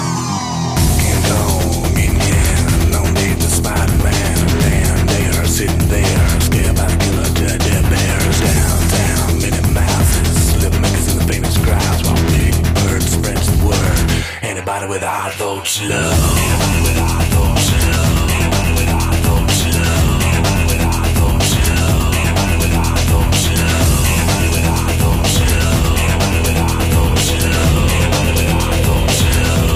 slick, sophisticated techno at a relentless pace